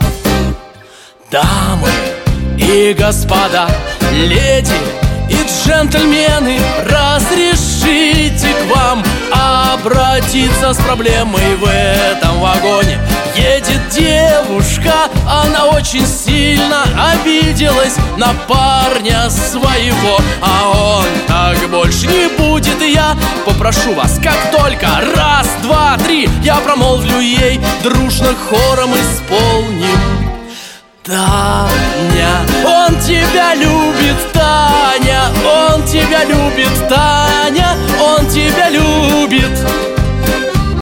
• Качество: 128, Stereo
шансон